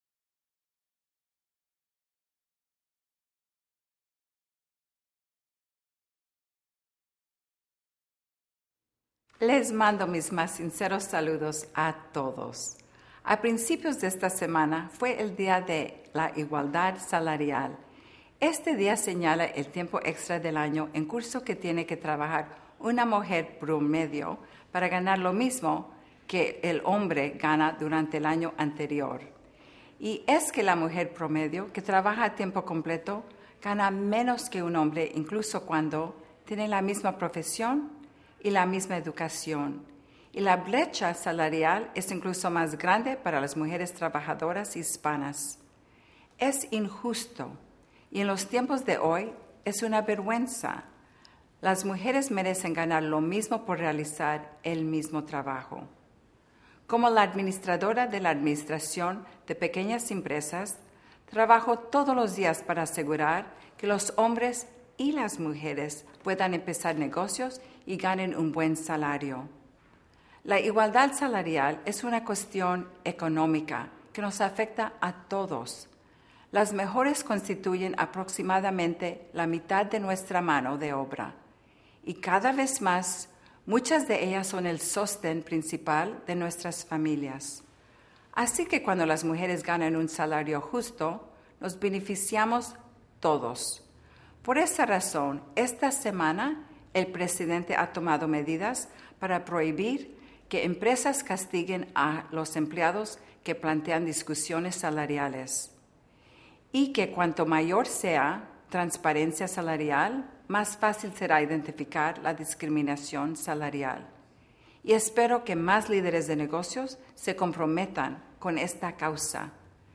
VIDEO MENSAJE DE LA CASA BLANCA: Asegurando Igualdad Salarial por el Mismo Trabajo Realizado April 12, 2014 | 4:02 | Public Domain En el mensaje de esta semana, María Contreras-Sweet, la nueva Administradora de la Administración de Pequeñas Empresas subrayó la importancia de asegurar la igualdad salarial por el mismo trabajo realizado y resaltó los pasos que el Presidente tomó para expandir las oportunidades y reducir la brecha salarial que existe entre los hombres y las mujeres.